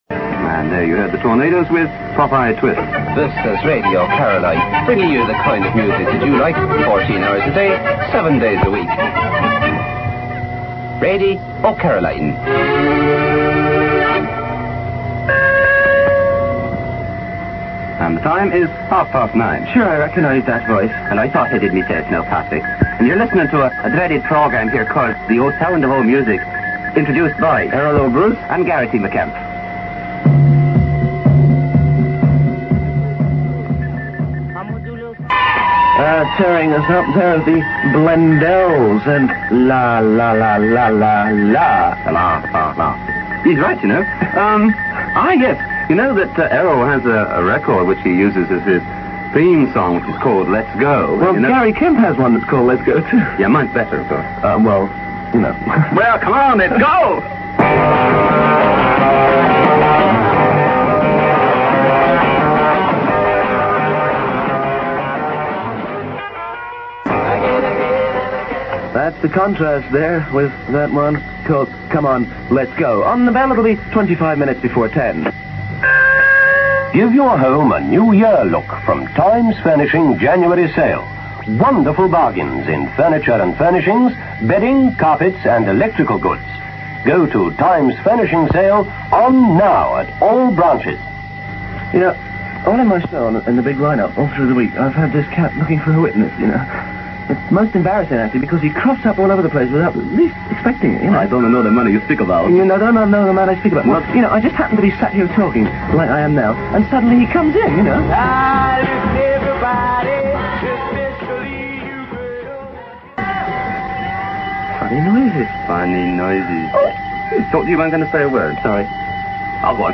putting on some fake Irish accents for an edition of The Sound Of Music on Radio Caroline South